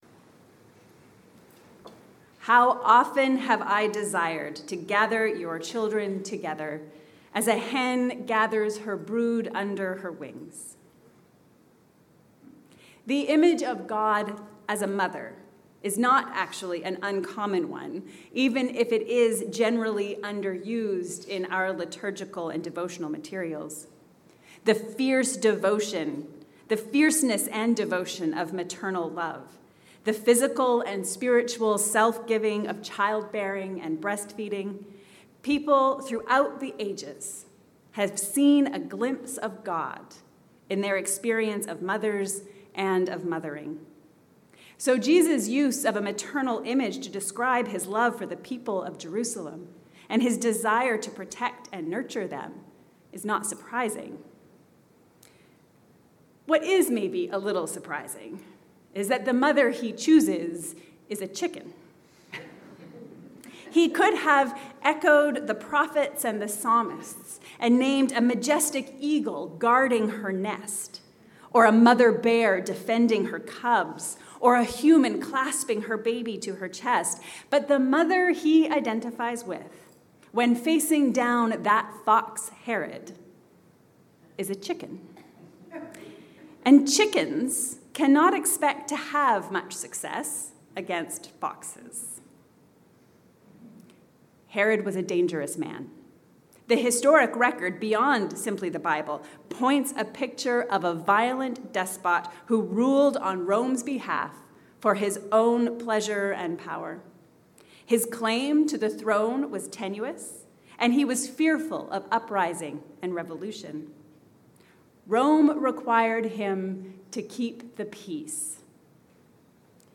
Hearts broken open. A sermon for the second Sunday in Lent